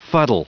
Prononciation du mot fuddle en anglais (fichier audio)
Prononciation du mot : fuddle